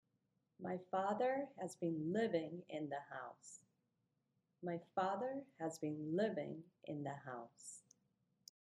Living にストレスが置かれる場合